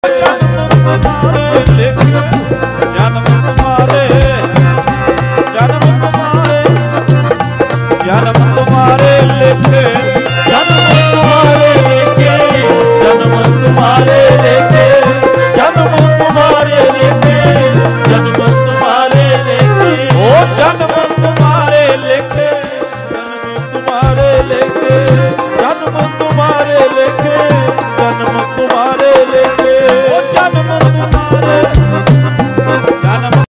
Dhadrian Wale mp3 downloads gurbani songs, sant ranjit singh dhadrianwale, dhadrianwale latest mp3, latest shabad, dhadrianwale latest dharna, dhadrianwale latest diwan, dhadrianwale latest poem